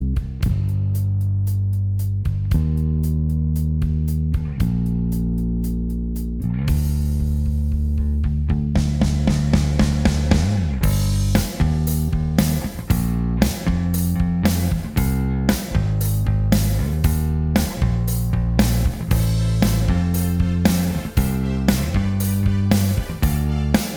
Minus All Guitars Pop (1970s) 4:34 Buy £1.50